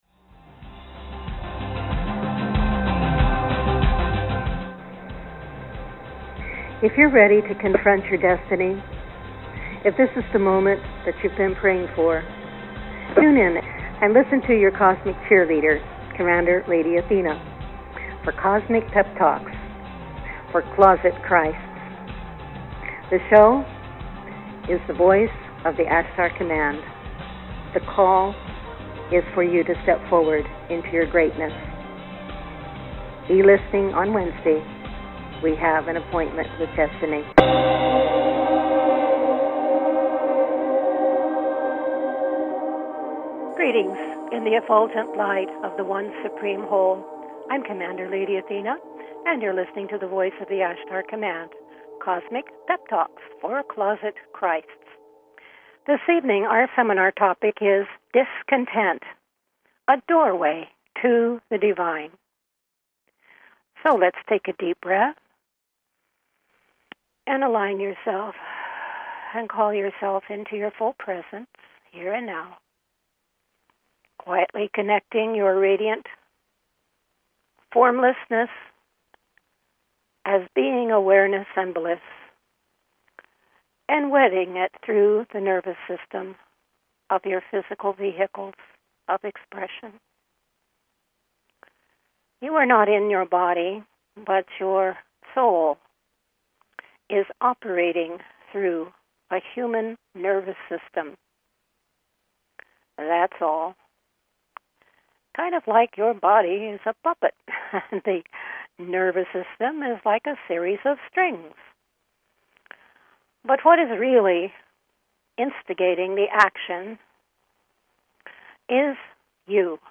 Talk Show Episode, Audio Podcast, The_Voice_of_the_Ashtar_Command and Courtesy of BBS Radio on , show guests , about , categorized as
Various experiential processes, meditations and teachings evoke your Divine knowing and Identity, drawing you into deeper com